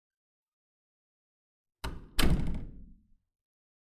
جلوه های صوتی
دانلود آهنگ زدن در به هم از افکت صوتی اشیاء
دانلود صدای زدن در به هم از ساعد نیوز با لینک مستقیم و کیفیت بالا